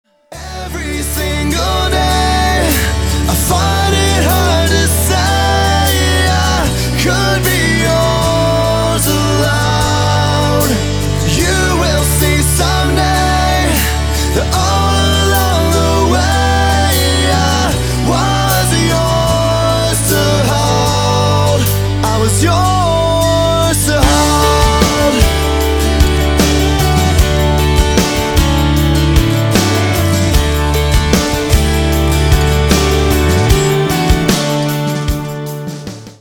• Качество: 320, Stereo
красивый мужской вокал
романтичные
христианский рок
Альтернативный рок